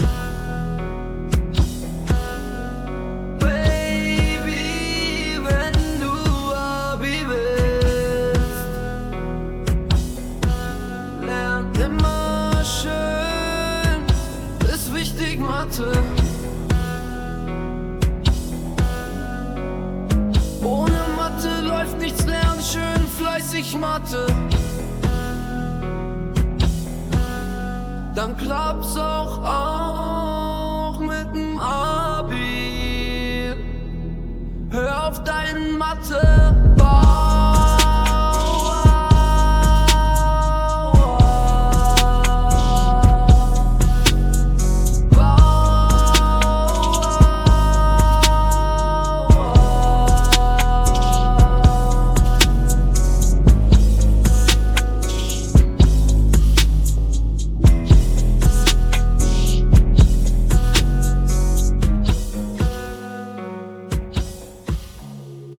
Musik: KI-generiert